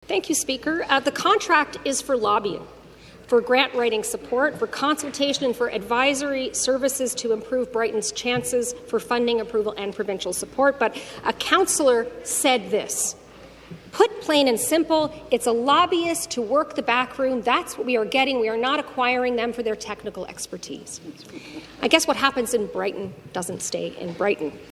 More from the Leader of the Opposition: